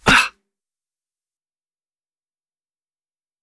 playerHurt3.wav